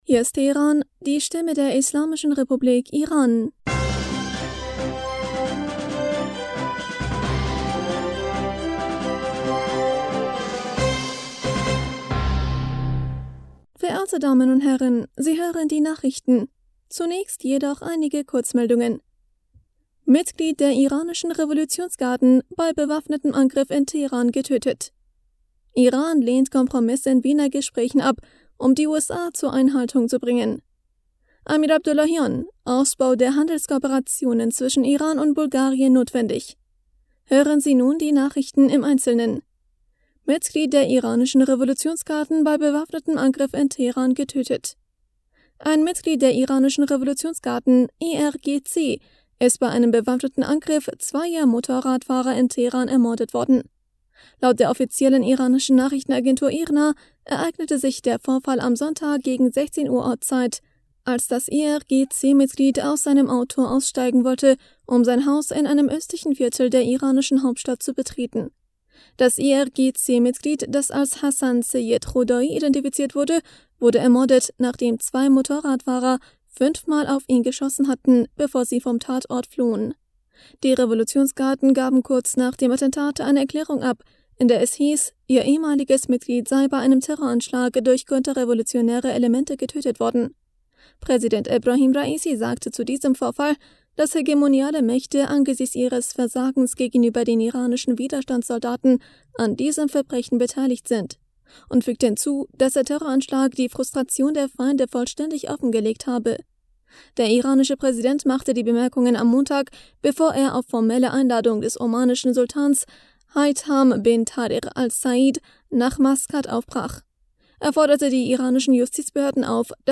Nachrichten vom 23. Mai 2022